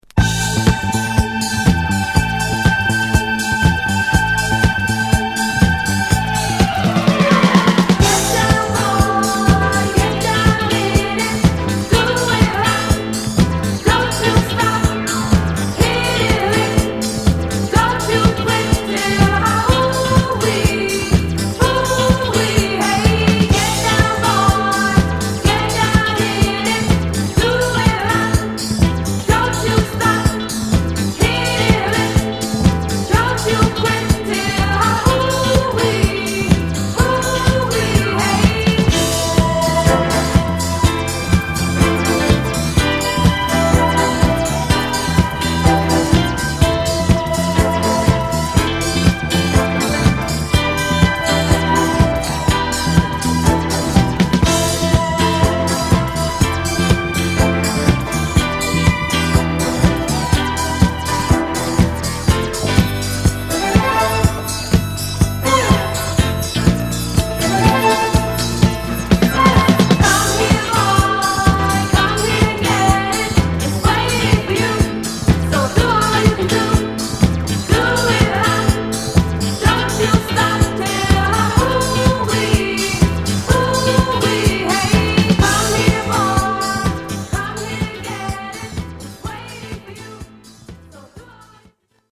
フランジャーをかけたハイハットやメロウディアスなピアノ、ストリングス等